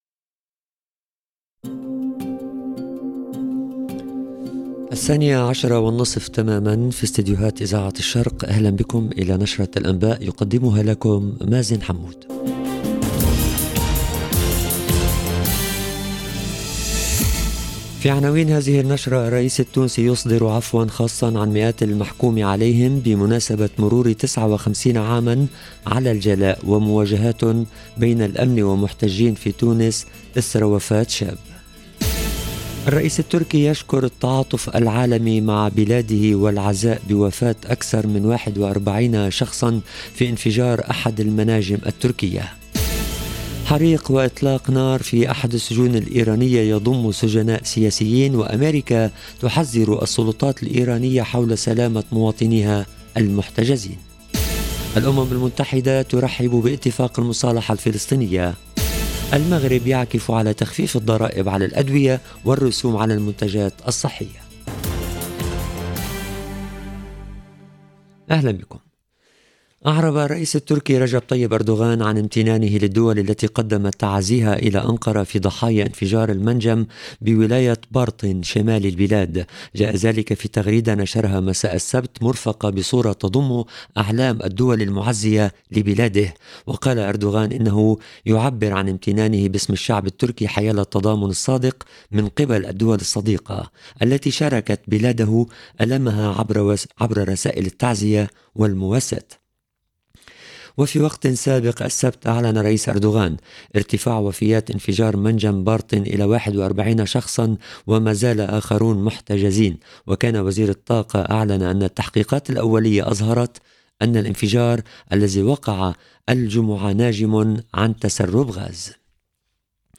LE JOURNAL EN LANGUE ARABE DE MIDI 30 DU 16/10/22